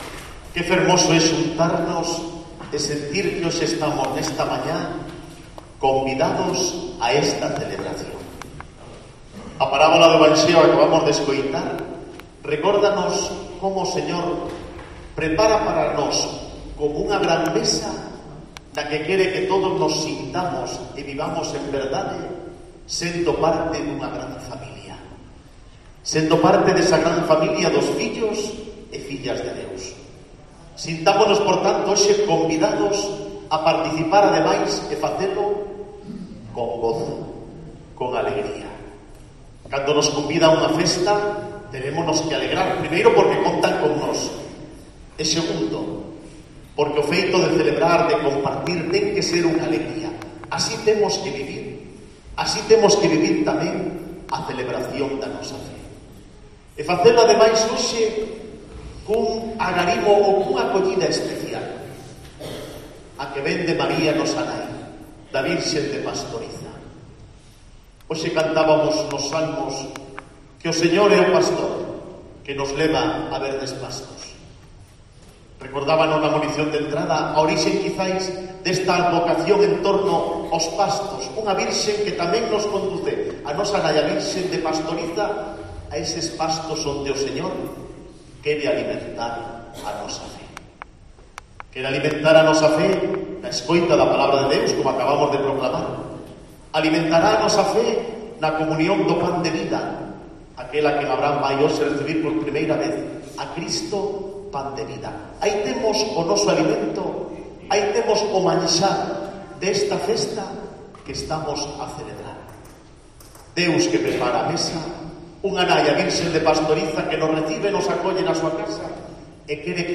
Homilía arzobispo de Santiago Virgen de Pastoriza